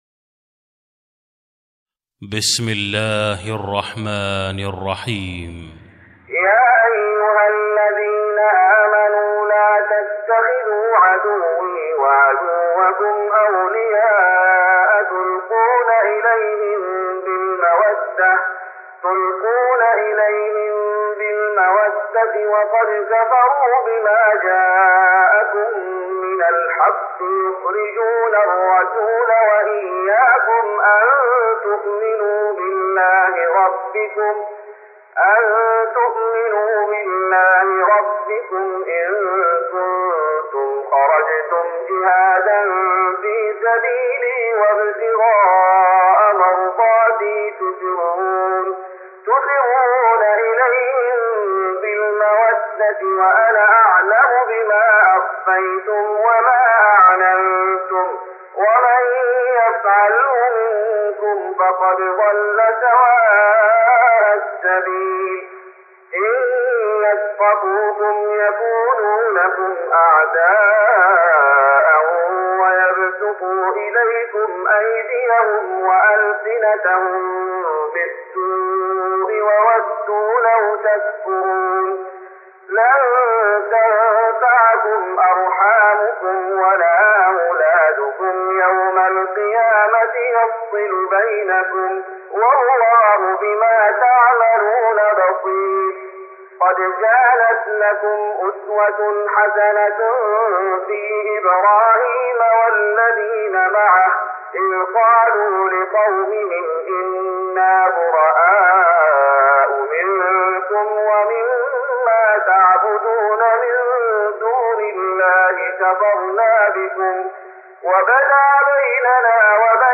تراويح رمضان 1414هـ من سورة الممتحنة Taraweeh Ramadan 1414H from Surah Al-Mumtahana > تراويح الشيخ محمد أيوب بالنبوي 1414 🕌 > التراويح - تلاوات الحرمين